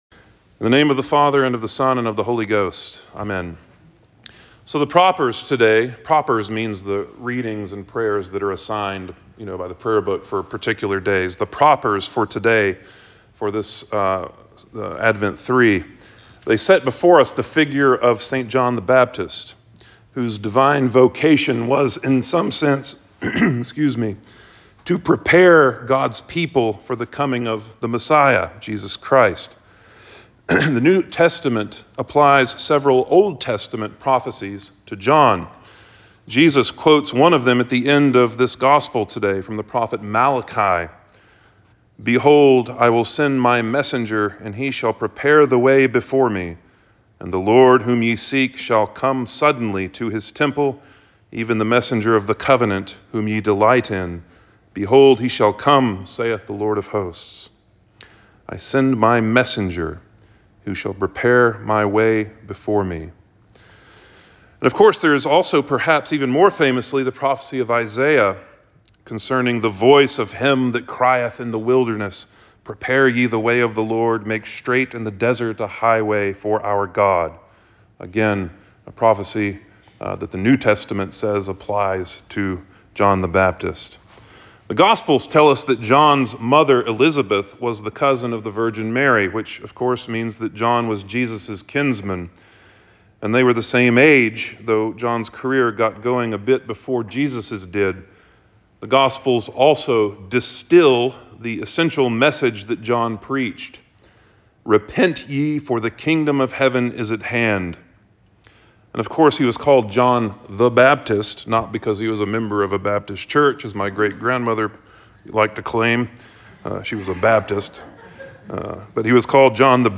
All Saints Sermons